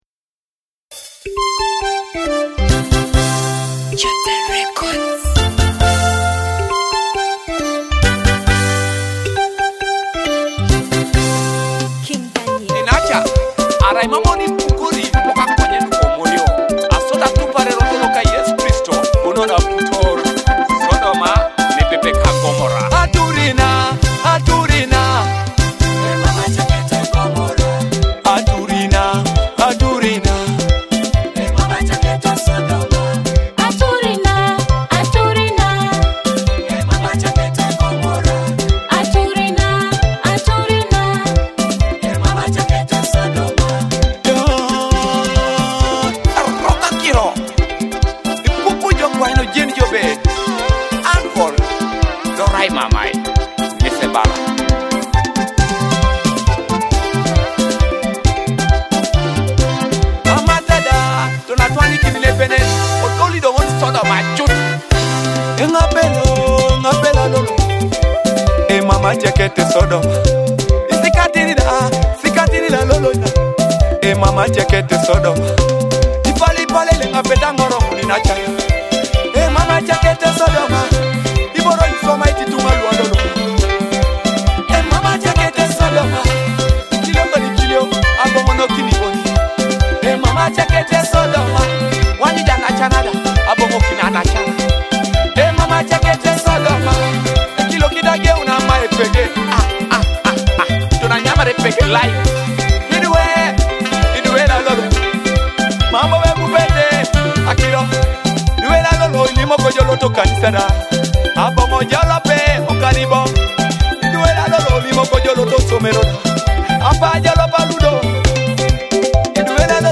a powerful gospel song
Through soul-stirring lyrics and passionate delivery